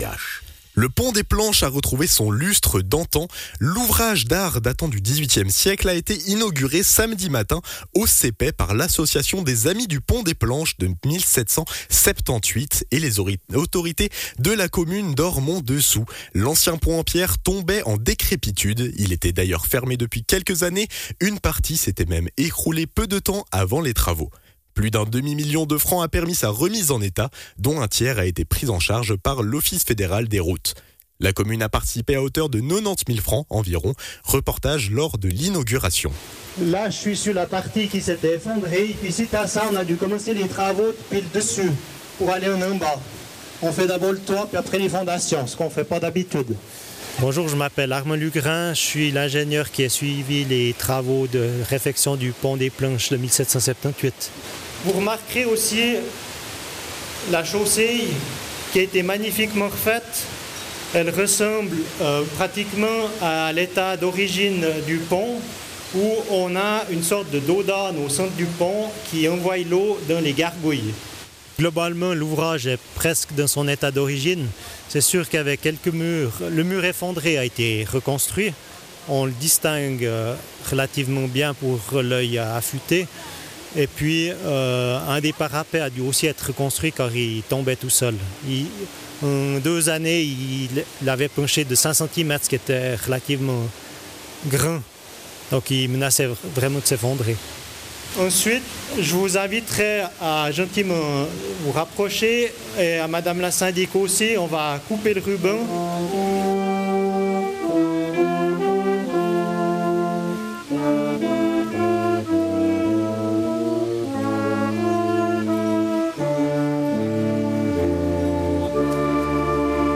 Ormont-Dessous: inauguration du Pont du Sépey, reportage